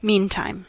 Ääntäminen
IPA : /ˈmiːntaɪm/